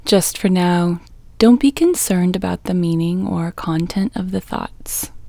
LOCATE OUT English Female 5